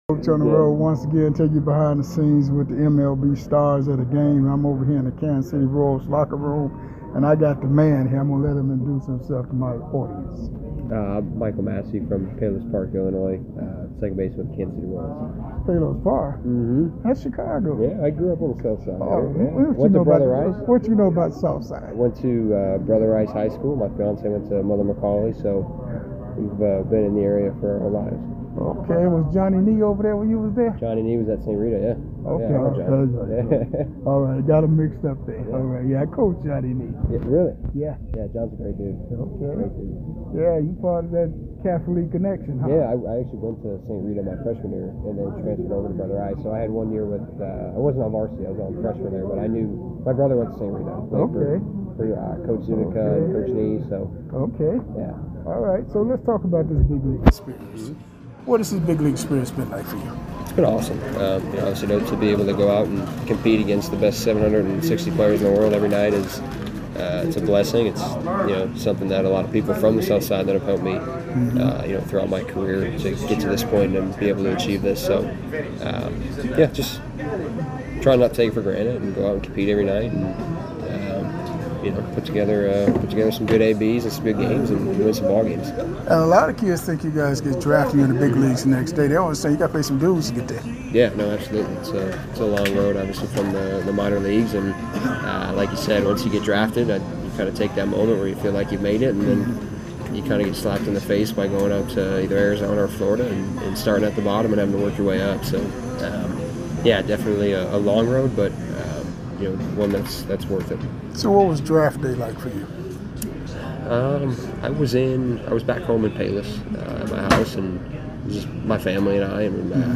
MLB Classic Interviews